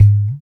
50 LO DRUM-L.wav